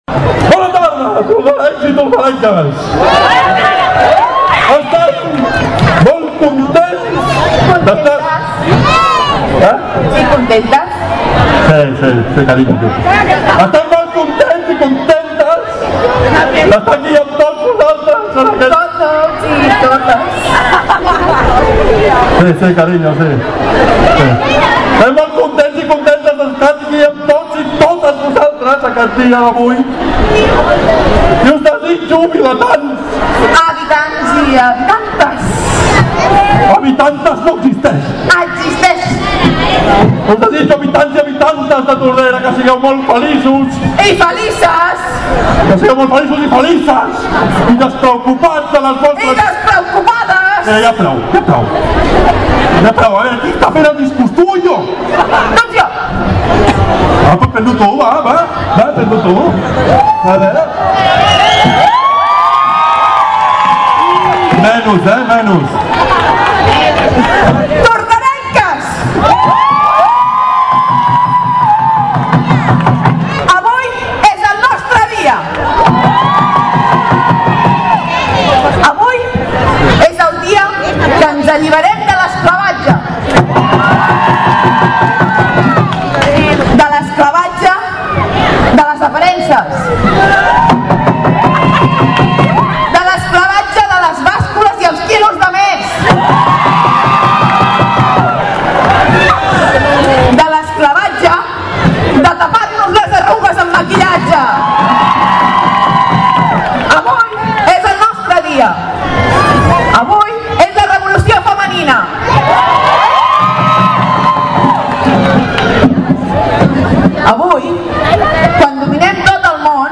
Abans, però, el Rei Carnestoltes va fer el seu discurs. I enguany va ser una mica especial, ja que va estar acompanyat de tota la família.
discurs carnestoltes